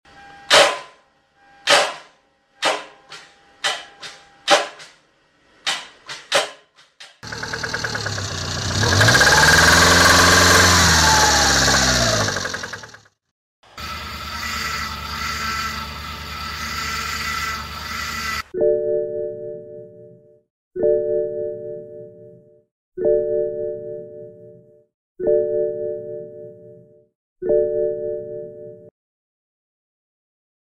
Surprise Car Trouble Sound at sound effects free download
Surprise Car Trouble Sound at the End